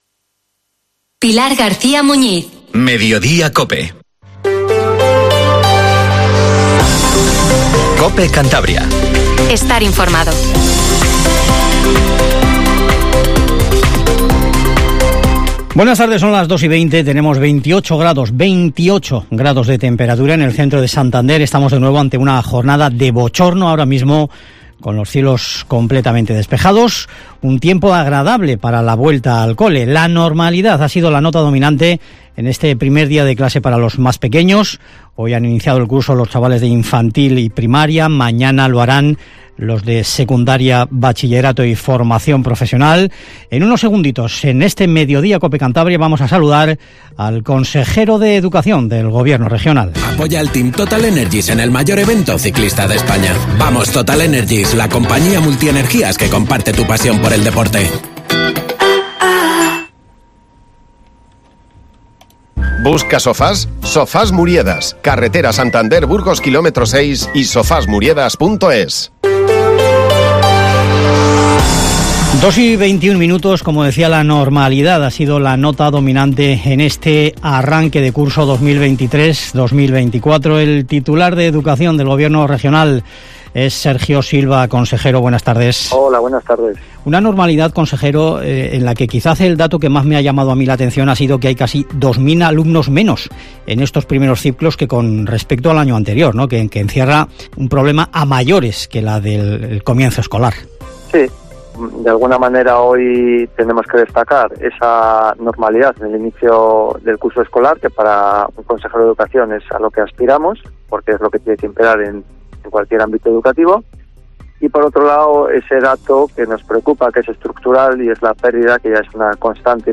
Informativo MEDIODIA COPE CANTABRIA 14:20